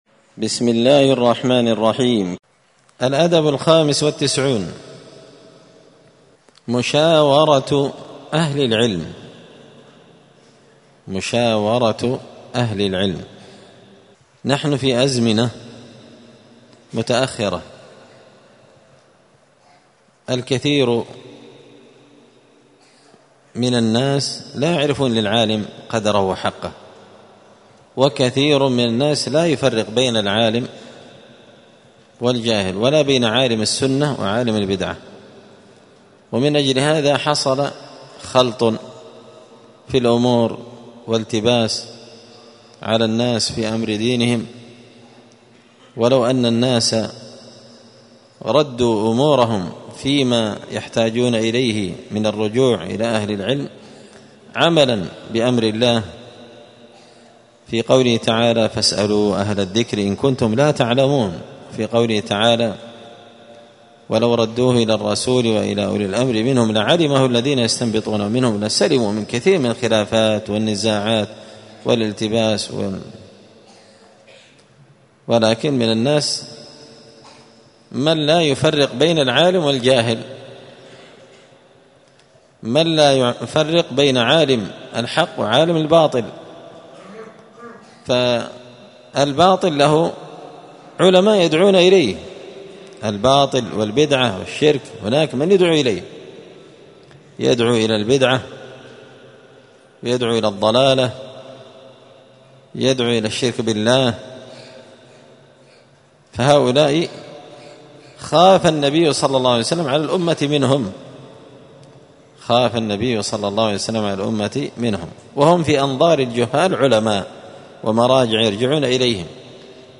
مسجد الفرقان قشن_المهرة_اليمن 📌الدروس الأسبوعية